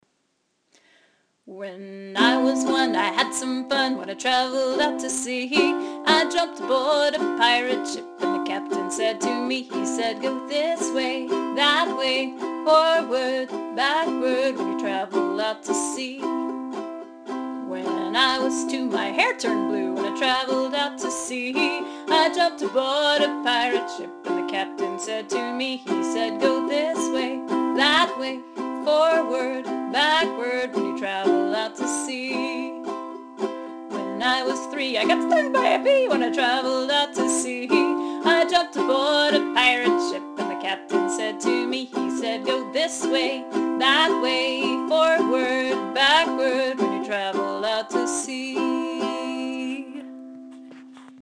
In any case, it’s a fun action song that gives the kids a chance to suggest rhymes. I usually sing it unaccompanied, but it’s easy to play on the ukulele with C and G7.